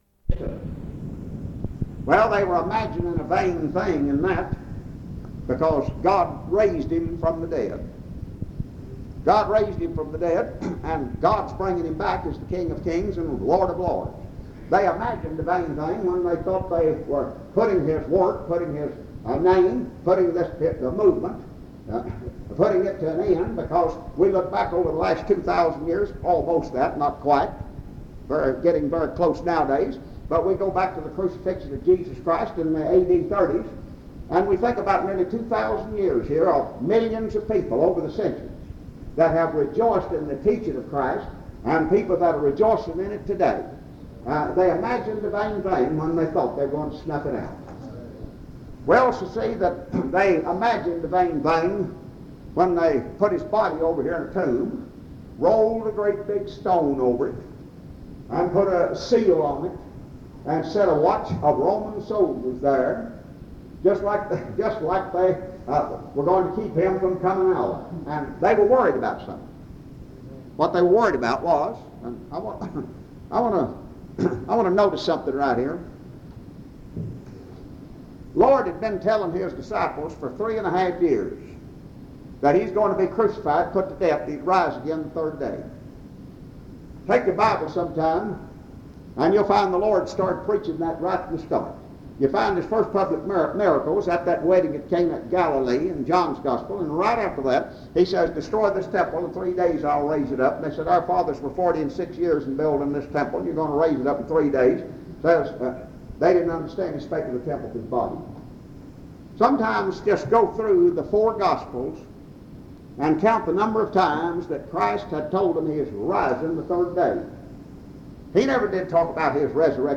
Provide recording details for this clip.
Walnut Cove (N.C.) Stokes County (N.C.)